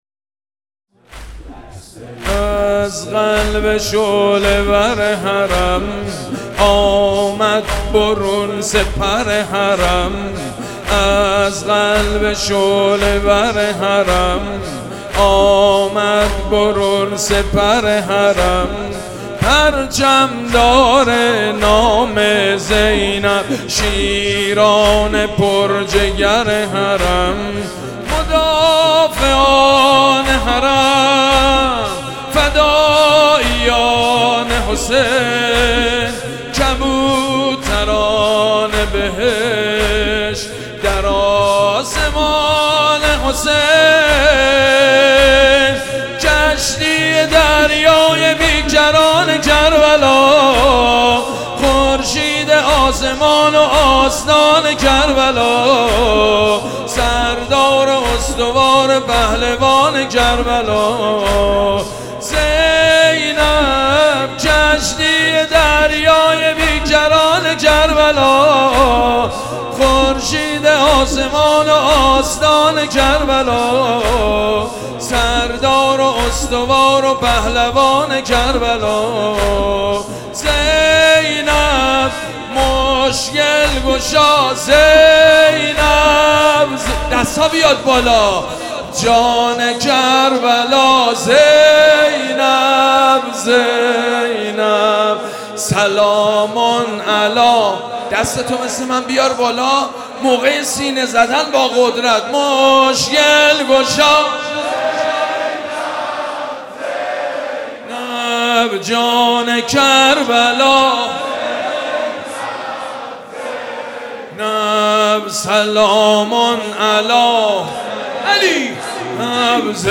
نوحه شب چهارم محرم ۱۴۰۲